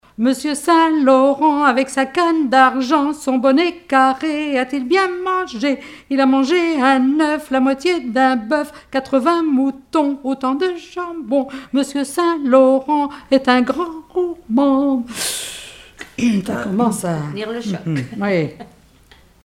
formulette enfantine : élimination
Rassemblement de chanteurs
Pièce musicale inédite